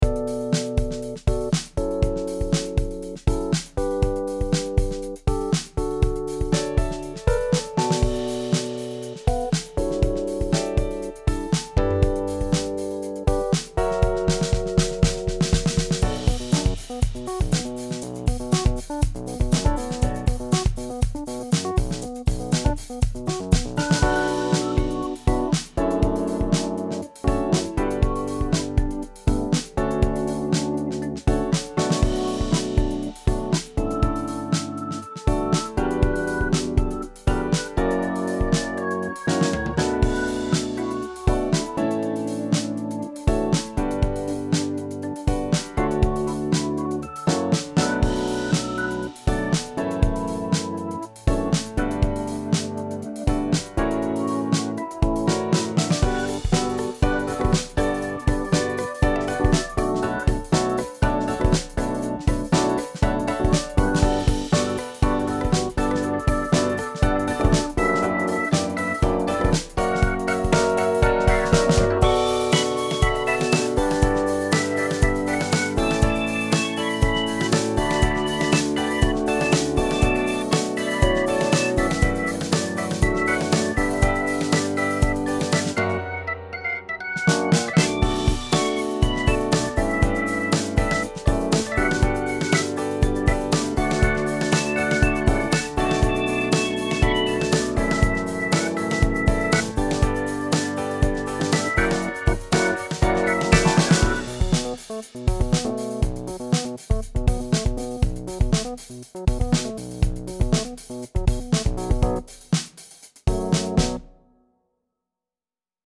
未分類 かっこいい ダンス ノリノリ 浸る夜 音楽日記 よかったらシェアしてね！